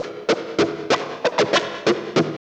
45 GUITAR -R.wav